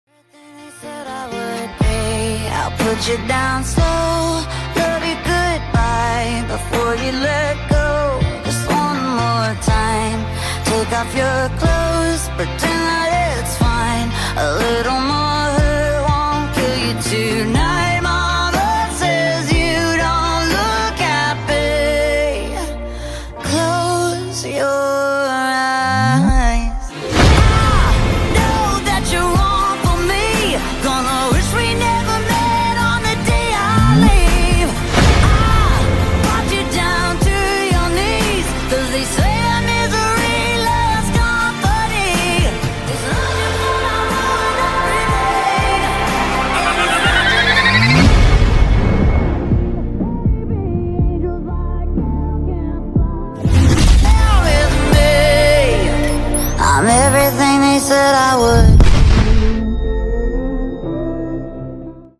background music (BGM)